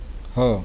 C. Uṣhm (Fricatives)
46_ha.mp3